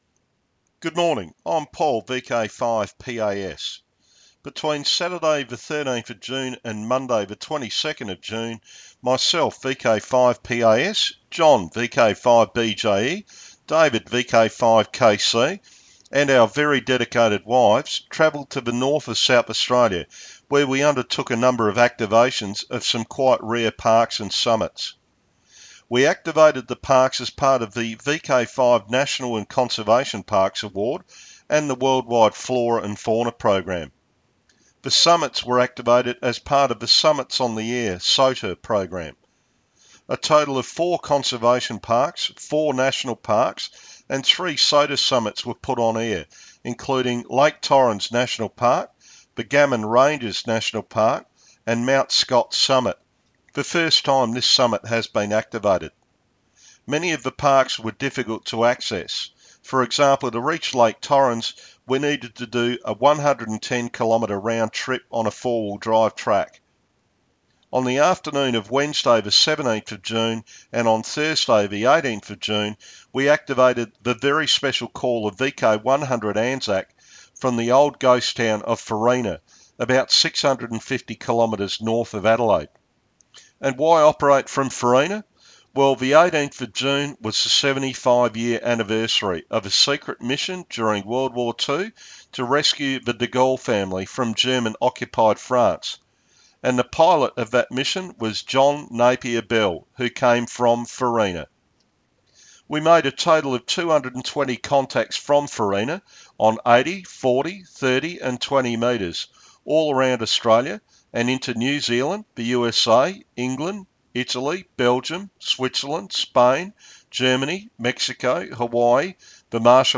He is some quick audio from the WIA Broadcast re our trip…..